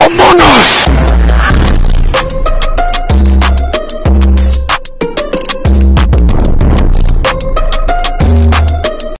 Among us Death Sound Effect
amogus_death_sfx_140.wav